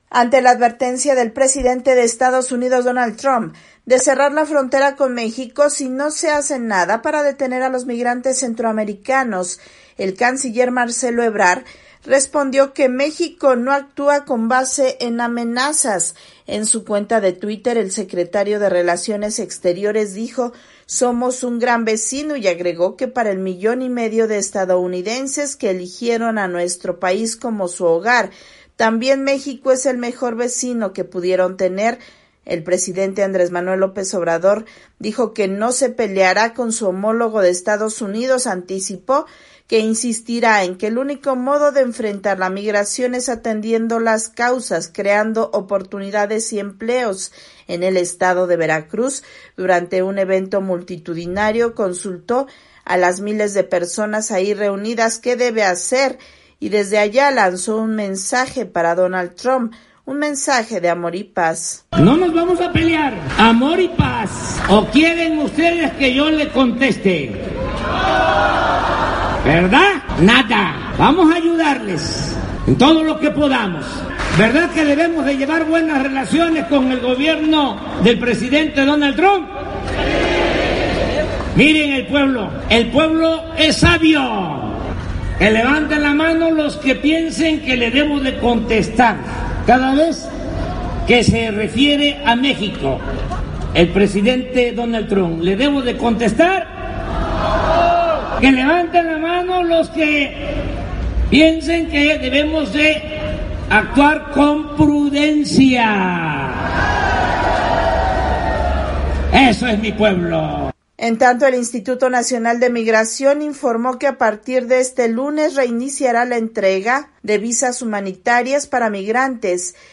En el estado de Veracruz, durante un evento multitudinario consultó a las miles de personas ahí reunidas qué debe hacer y desde allá lanzó para Donald Trump un mensaje de amor y paz.
VOA: Informe desde México